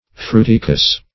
fruticous - definition of fruticous - synonyms, pronunciation, spelling from Free Dictionary Search Result for " fruticous" : The Collaborative International Dictionary of English v.0.48: Fruticous \Fru"ti*cous\, a. (Bot.)